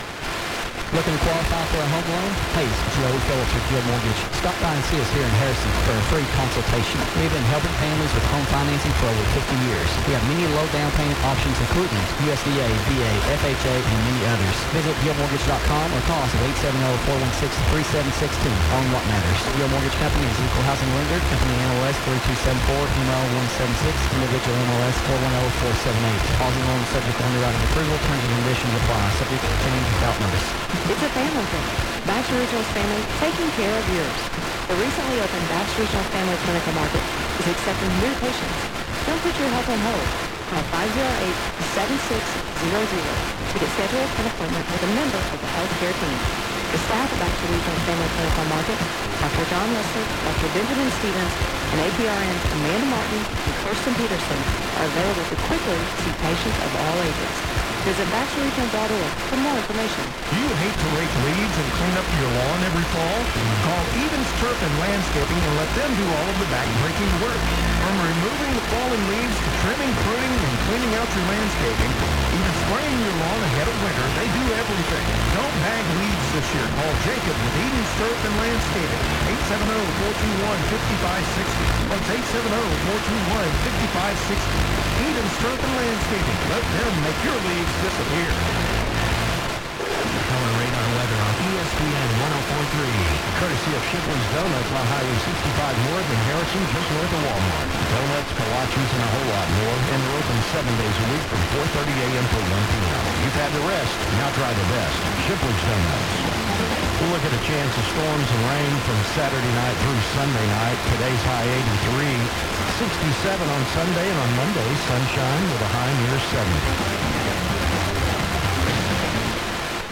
by | Oct 5, 2019 | FM DX | 0 comments